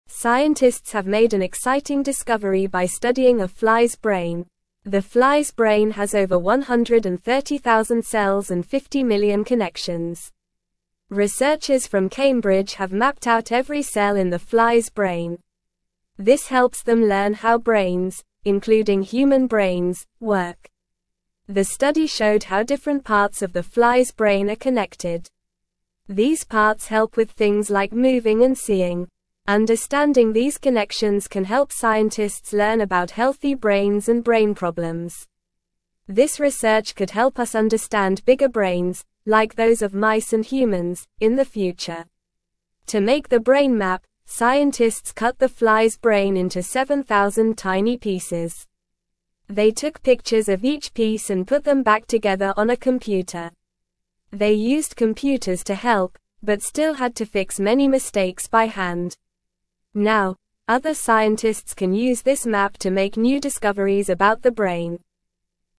Normal
English-Newsroom-Lower-Intermediate-NORMAL-Reading-Scientists-study-tiny-fly-brain-to-learn-more.mp3